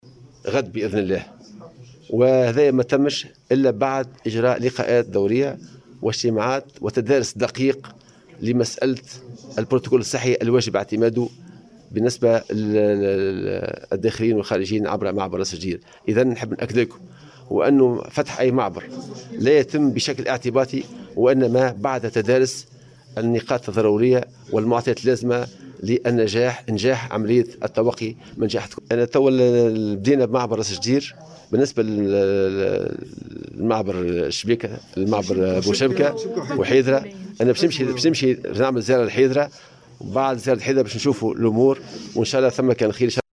وأضاف في تصريح لمراسل "الجوهرة أف أم" اليوم على هامش إشرافه على تنصيب والي القصرين الجديد عادل مبروك، أن فتح المعابر الحدودية لا يتم بشكل اعتباطي بل بعد تدارس دقيق للبرتوكول الصحي الواجب اعتماده.